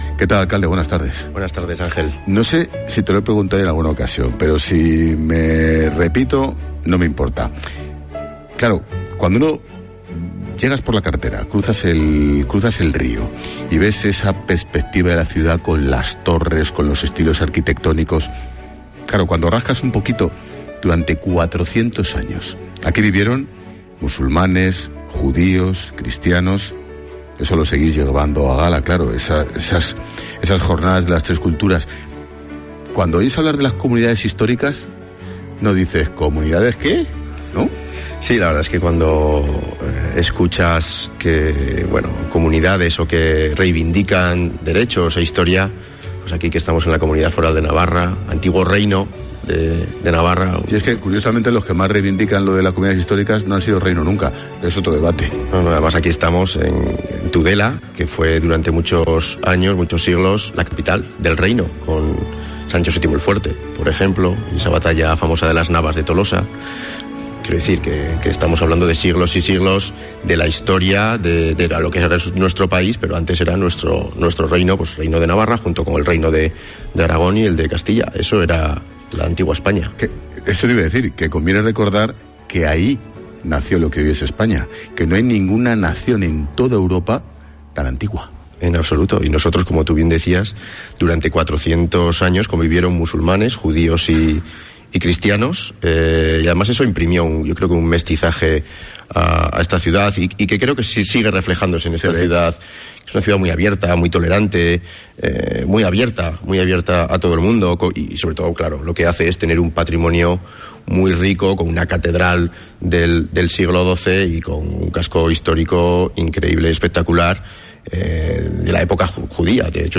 AUDIO: ENTREVISTA DE ÁNGEL EXPÓSITO CON EL ALCALDE ALEJANDRO TOQUERO